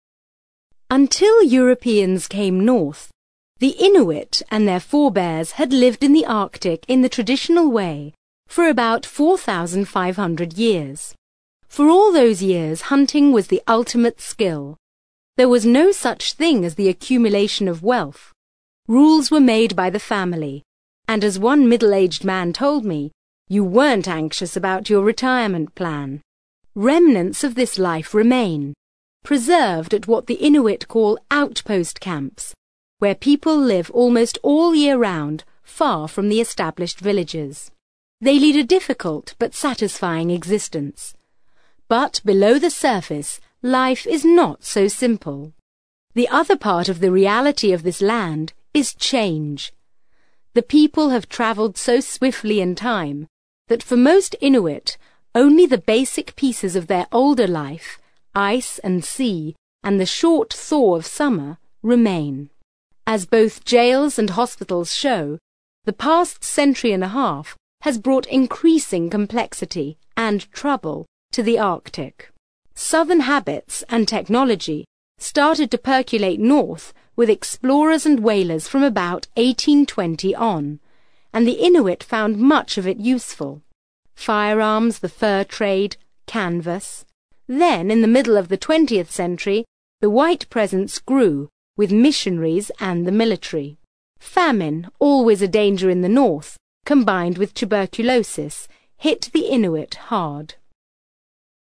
ACTIVITY 31: You are going to hear a short extract from a radio documentary.